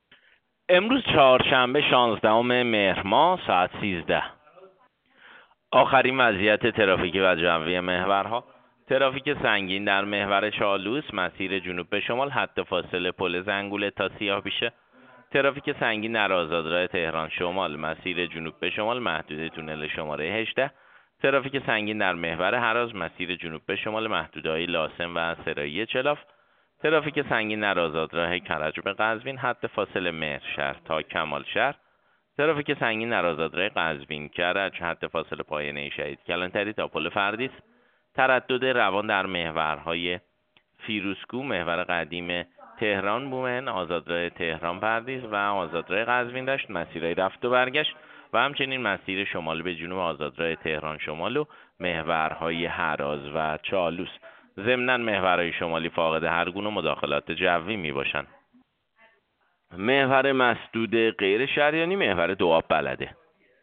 گزارش رادیو اینترنتی از آخرین وضعیت ترافیکی جاده‌ها ساعت ۱۳ شانزدهم مهر؛